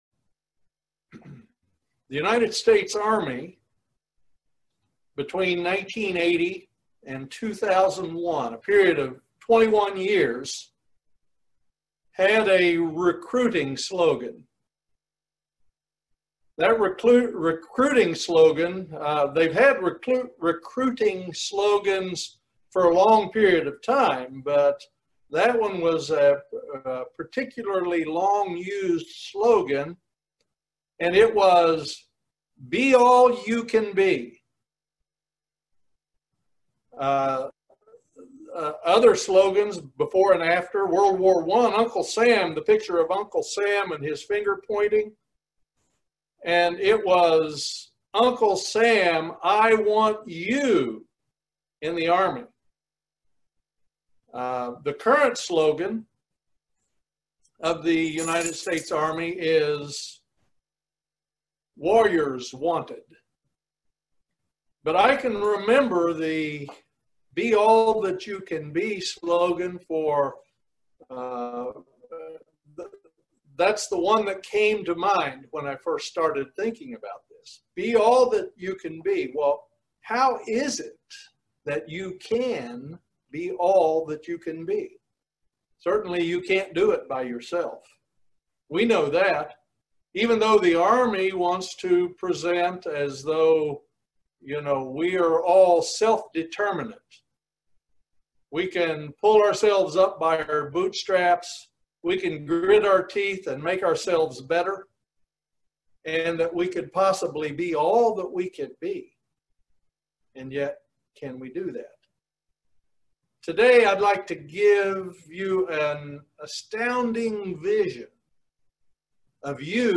Very eye opening video sermon about being all we can be, and meeting God's expectations.
Given in Lexington, KY